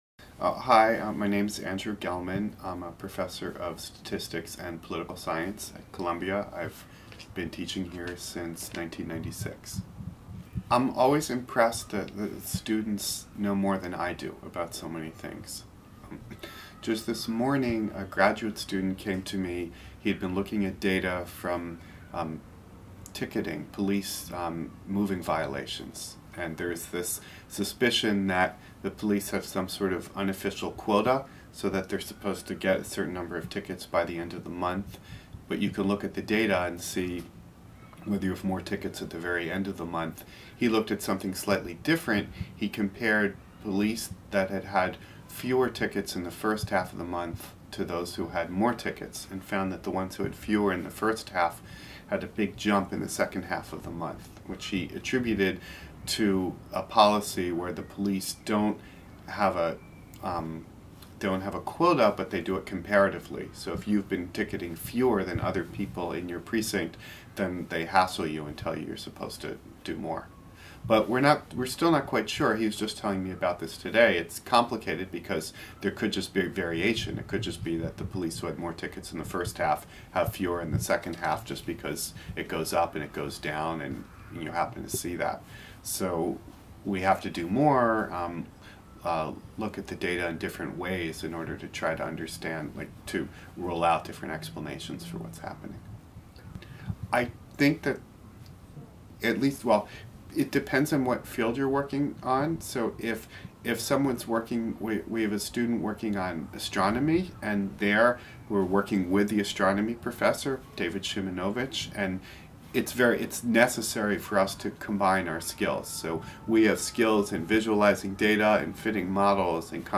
In the following chunk we use a whisper model to transcribe a short speech from an .mp3 file